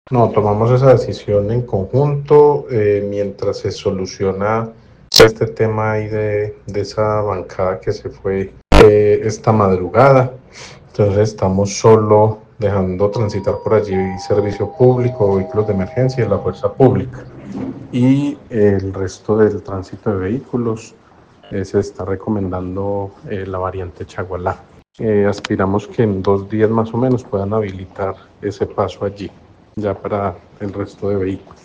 Daniel Jaime Castaño, secretario de tránsito de Armenia